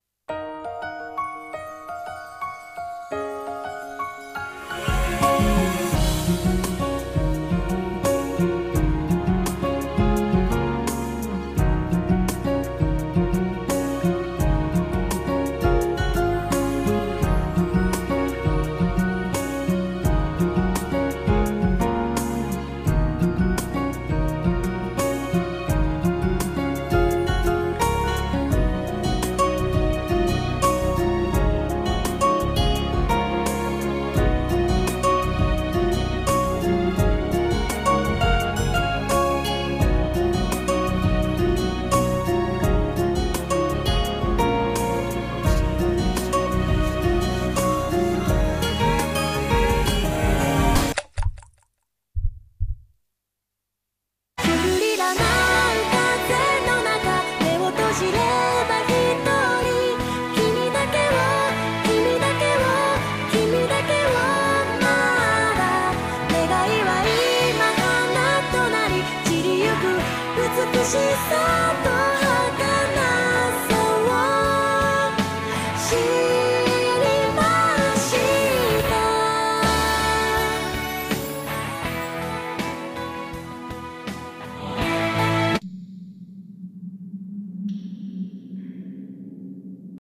CM風声劇「眠る花」 / リリー:【】ビオラ:【】